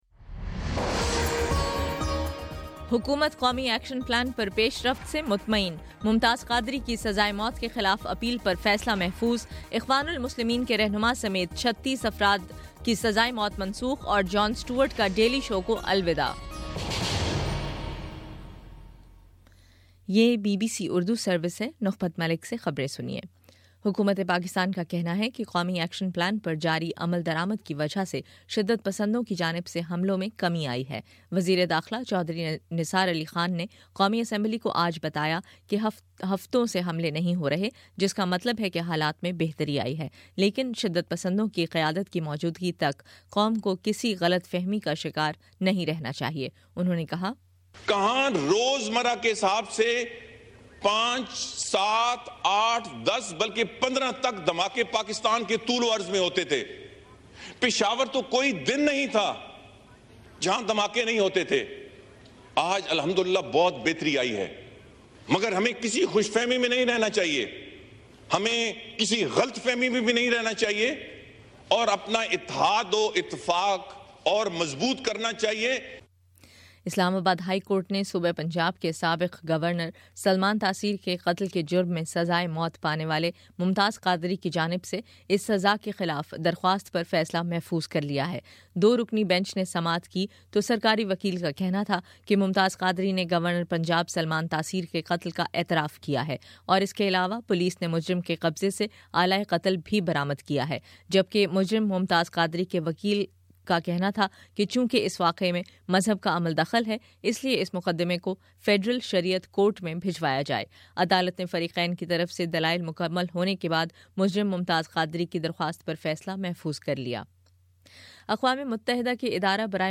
فروری11: شام سات بجے کا نیوز بُلیٹن